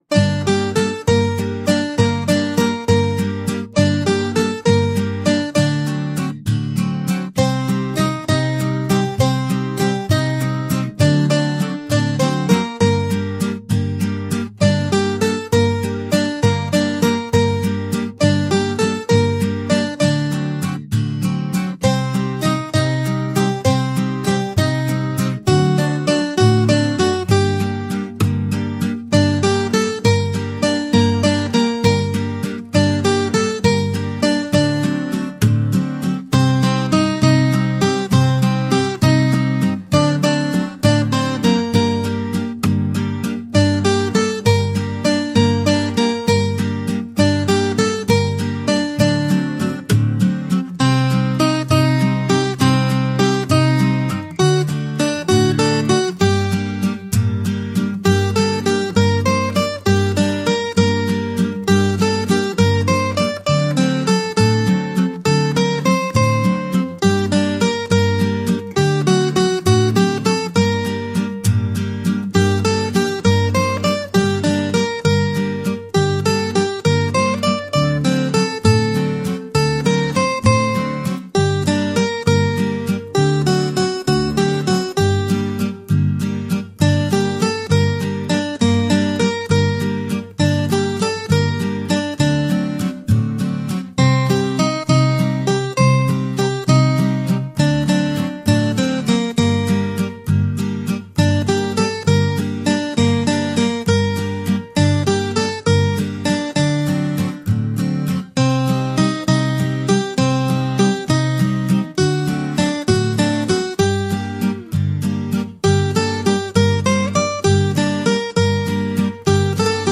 Трёхдольный ритм (¾)
Плавные, текучие мелодии
Романтичное и лирическое настроение